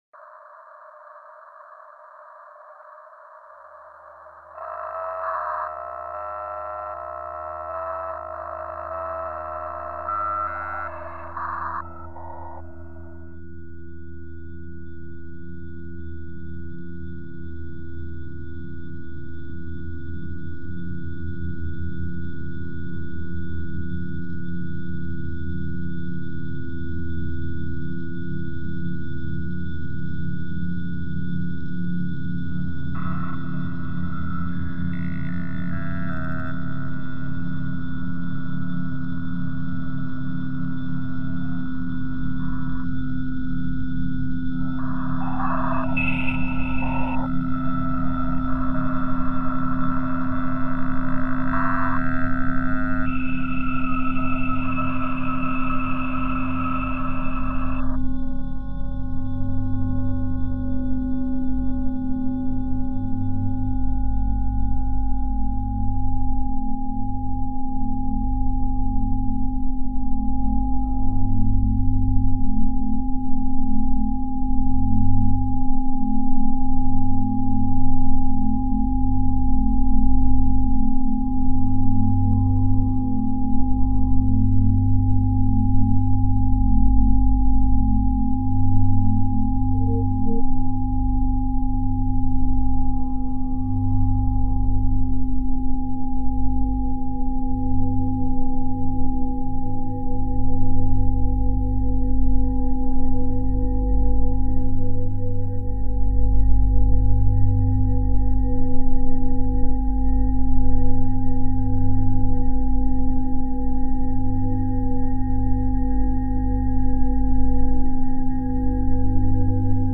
File under: Experimental / Minimal Ambient / Drone Music